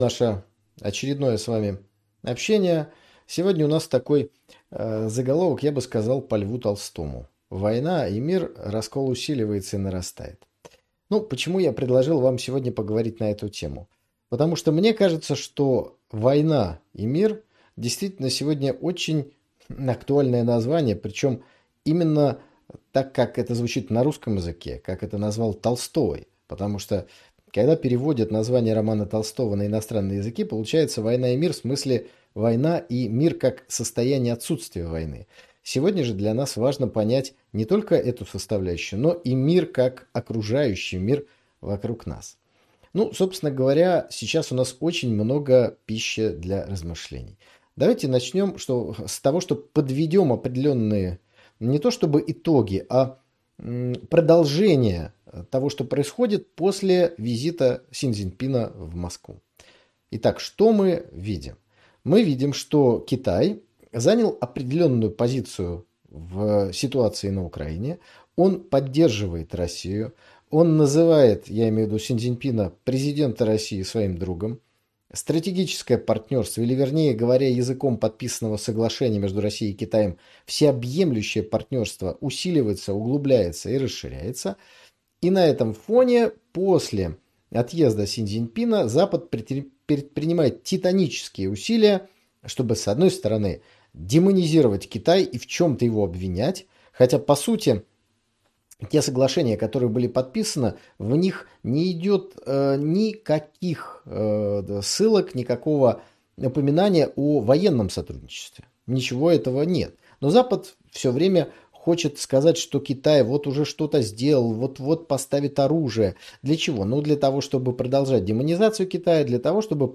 В очередном прямом эфире выходного дня говорили о напряженной геополитической обстановке в мире.